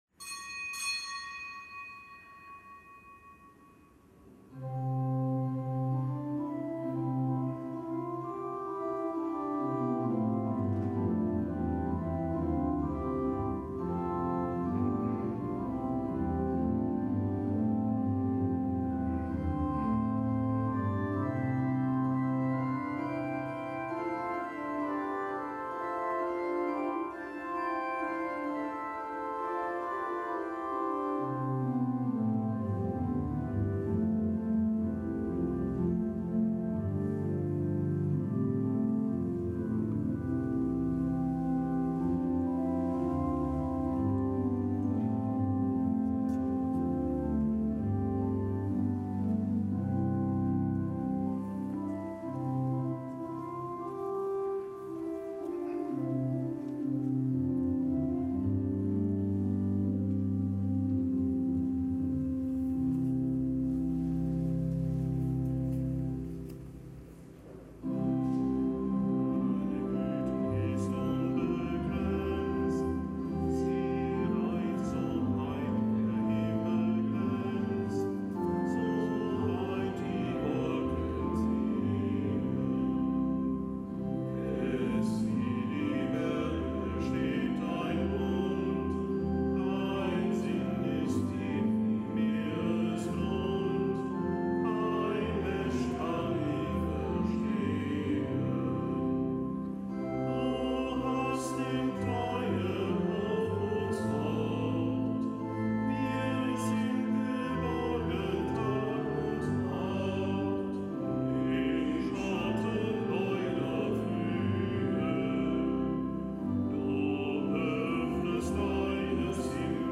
Kapitelsmesse aus dem Kölner Dom am Mittwoch der fünfundzwanzigsten Woche im Jahreskreis, Nichtgebotener Gedenktag Heiliger Rupert und heilige Virgil, Bischöfe von Salzburg, Glaubensboten (RK).